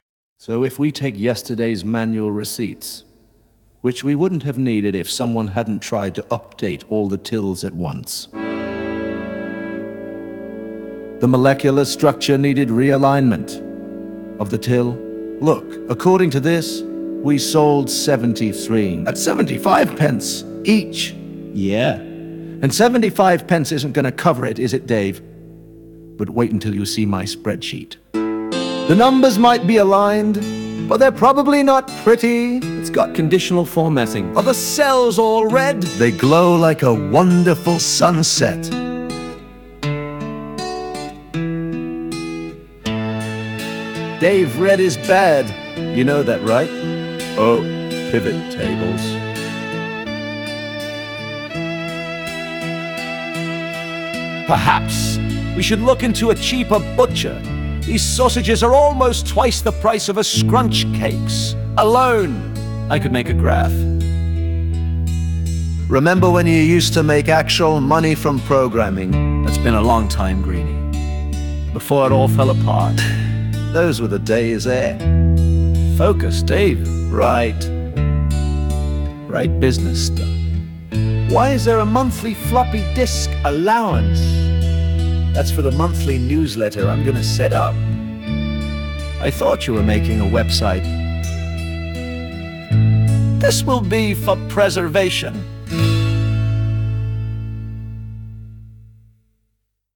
Then I remembered that Suno can occasionally be used for "Not songs", and I wondered how well that might do a spoken episode of Codas.